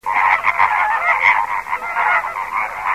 głosy